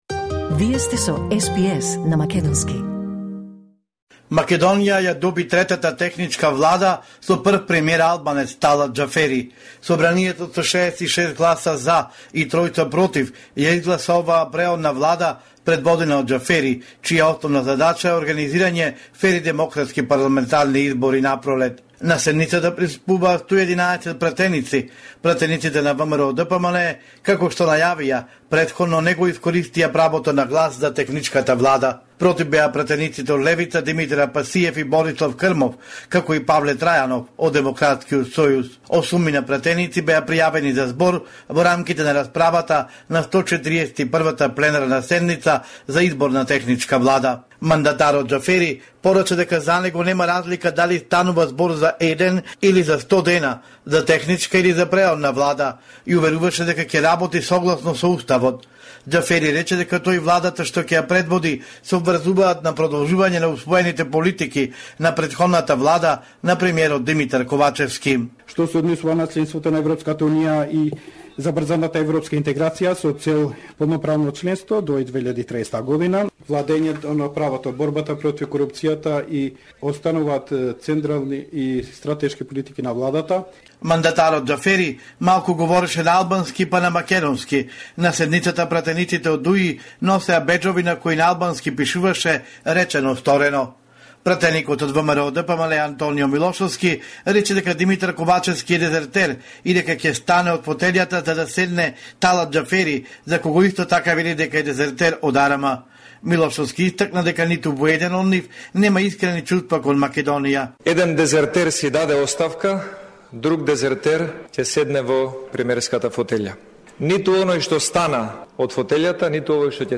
Homeland Report in Macedonian 29 January 2024